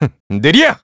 Riheet-Vox_Skill2_kr.wav